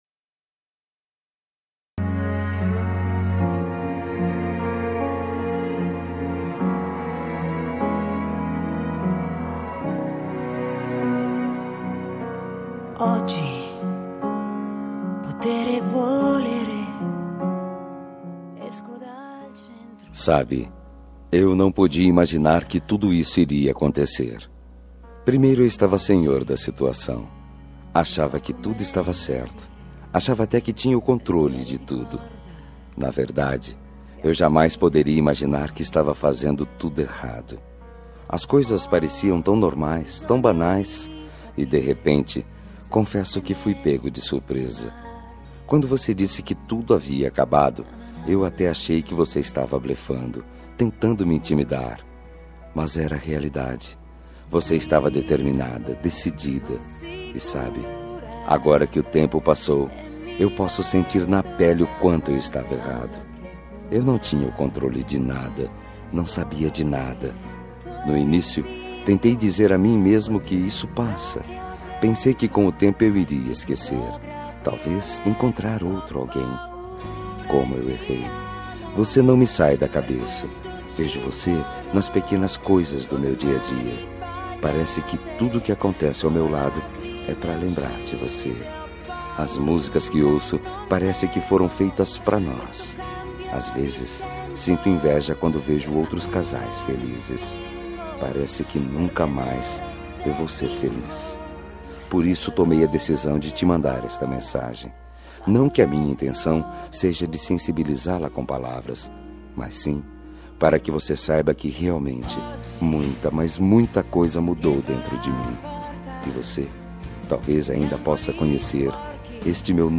Telemensagem de Reconciliação Romântica – Voz Masculina – Cód: 948